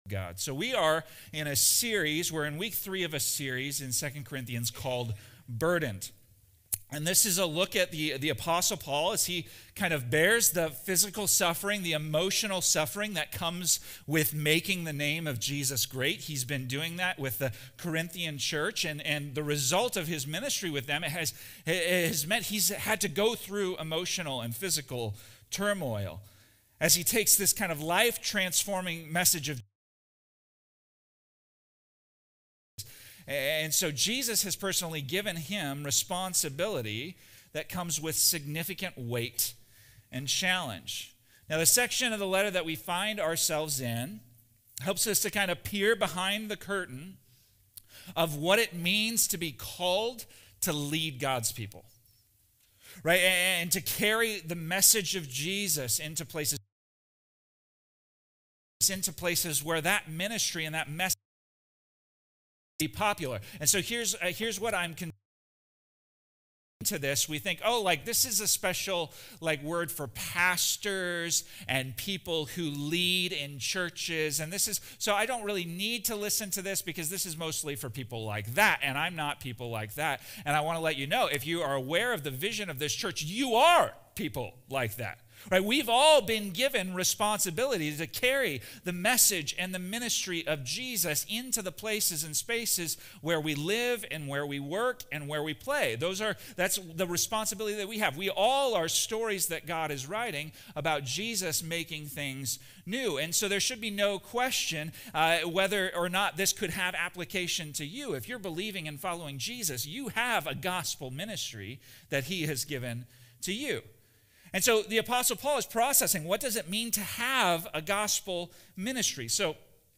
In this powerful sermon from 2 Corinthians 6:1-10, we explore the weight of the gospel and the urgency of responding to God's grace. The Apostle Paul endured hardships, opposition, and suffering, yet he remained committed to the call of Christ.